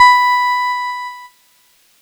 Cheese Note 17-B3.wav